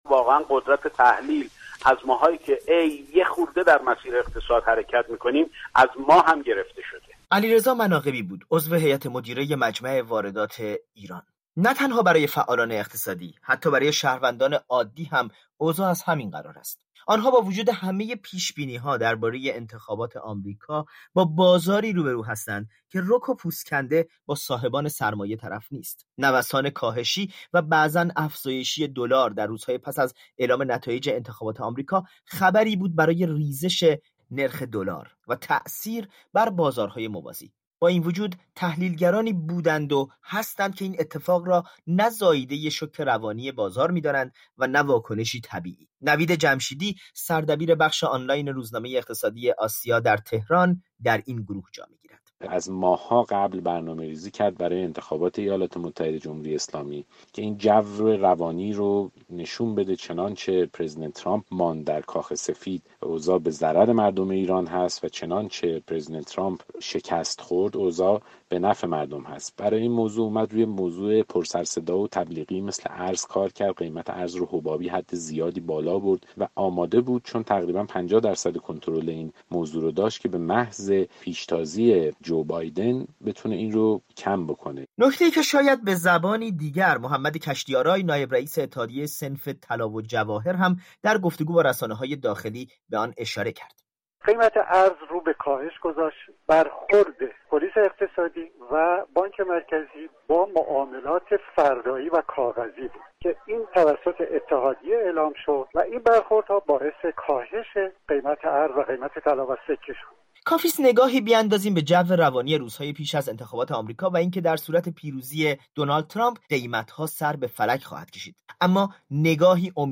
در گزارشی از گره خوردن اوضاع سیاسی داخلی و بین المللی بر این چشم انداز می گوید